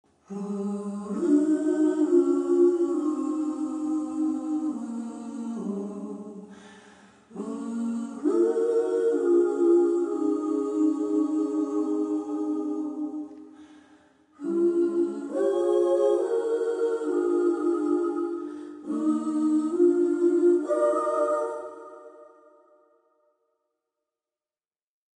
sorry for got to inlude the mp3 vietnames call " nhac be`"